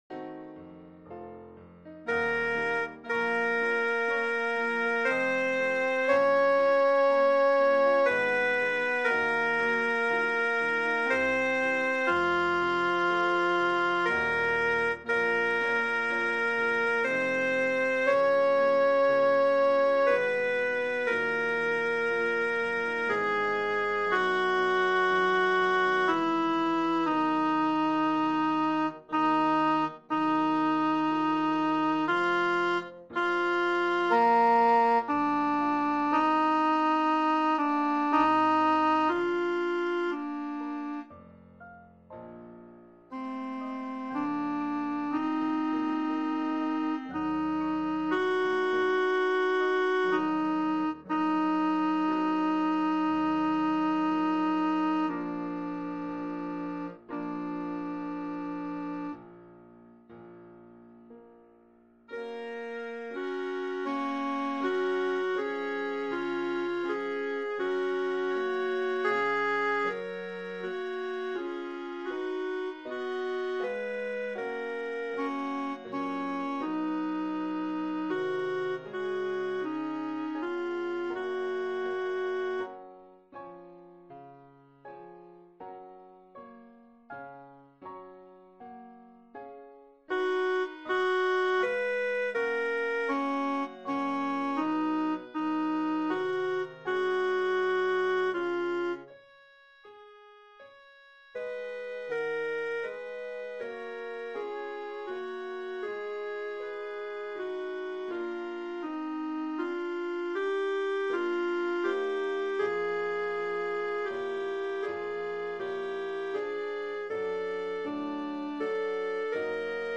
avec chaque voix accentuées
et parfois l'orchestre en fond